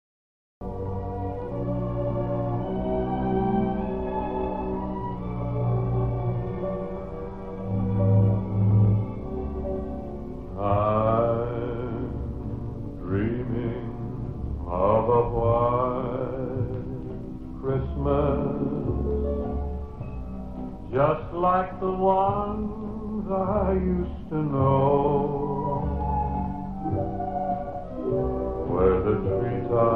Genre: Christmas